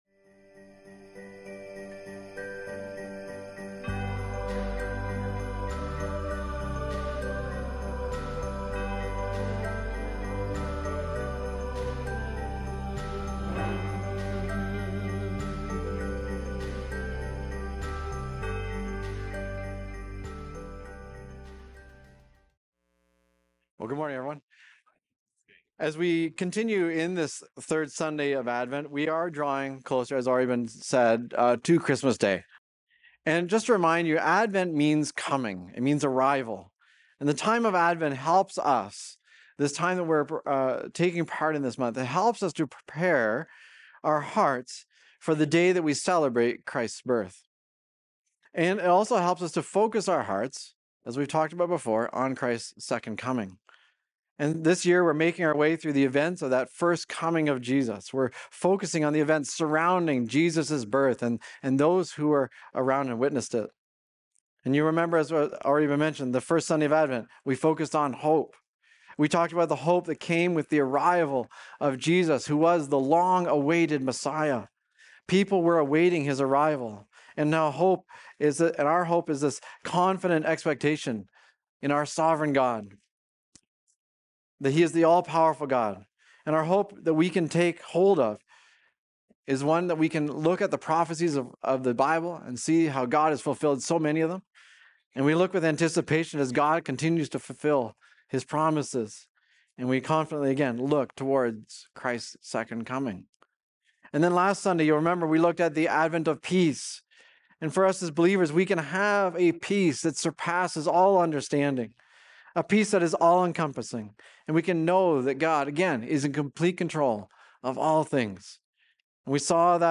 Recorded Sunday, December 14, 2025, at Trentside Fenelon Falls.